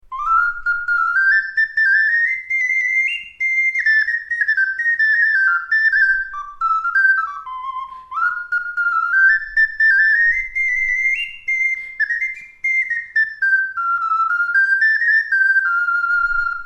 Окарина Focalink 12SCB-G
Керамическая окарина - сопрано.
Диапазон неполные две октавы. Классическая модель сочетающая в себе превосходный звук и легкость управления.